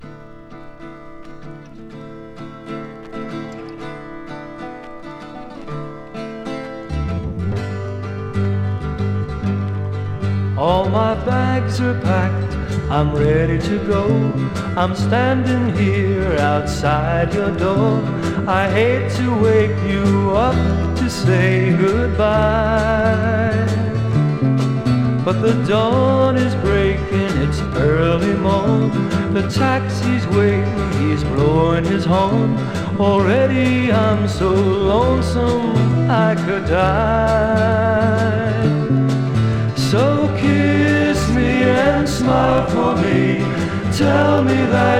Rock, Pop　USA　12inchレコード　33rpm　Mono